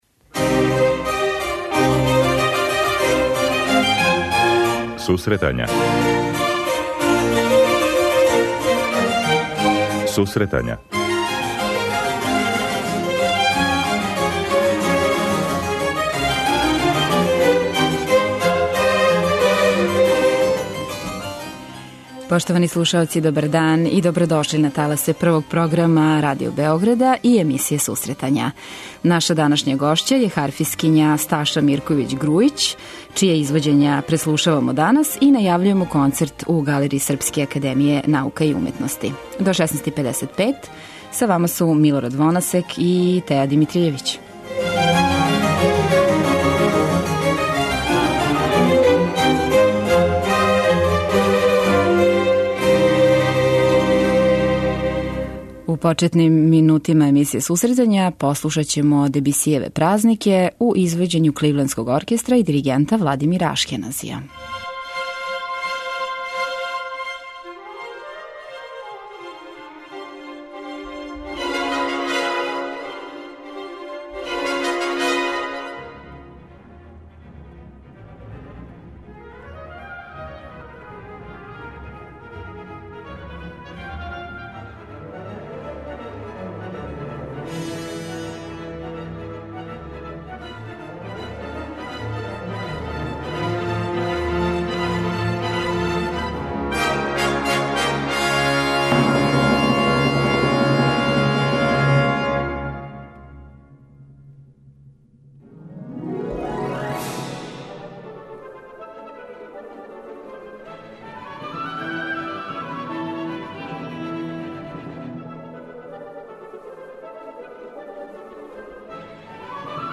Најављујемо концерт у Галерији САНУ и преслушавамо извођења ове уметнице.